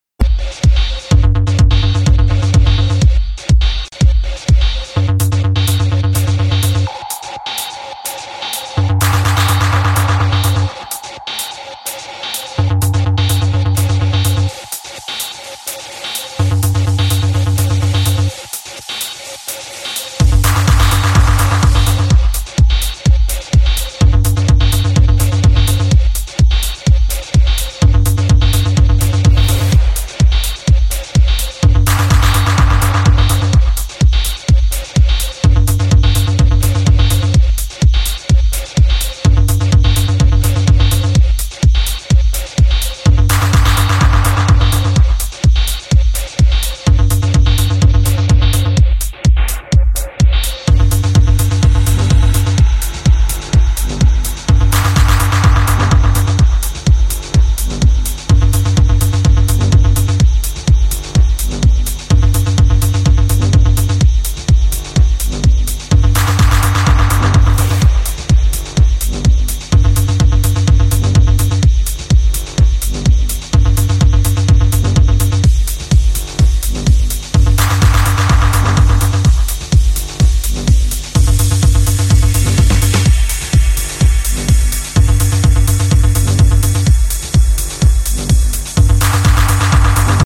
Fierce banging techno tracks!
Techno